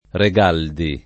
[ re g# ldi ]